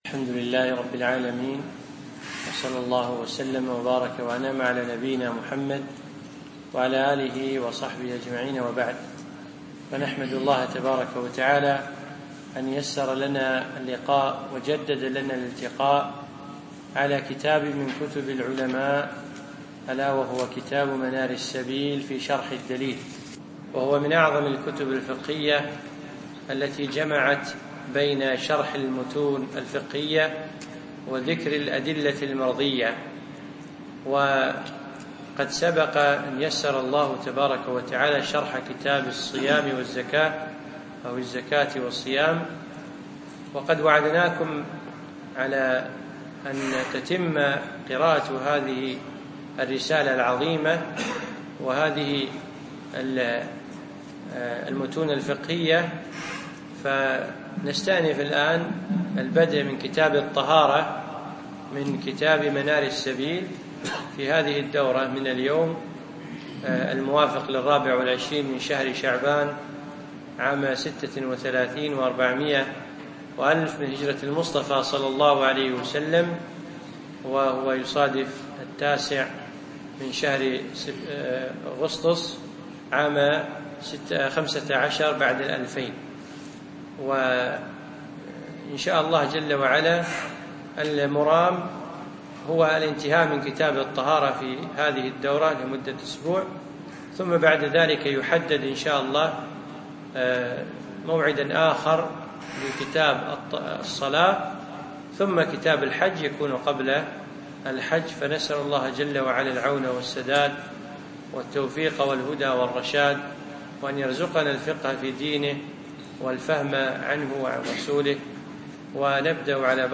يوم الاحد 9 8 2015 في مسجد أحمد العجيل القصور